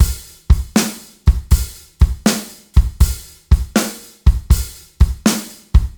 Drum Loops
Blues 2
Swing / 80 / 2 mes
BLUES1-OH-80.mp3